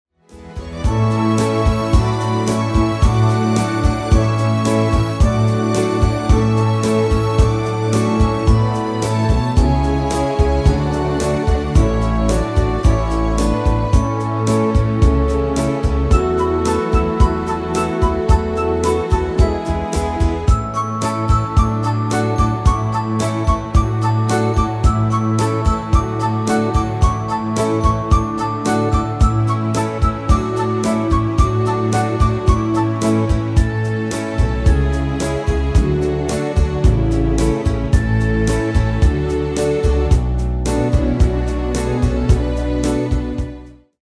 Extended MIDI File Euro 12.00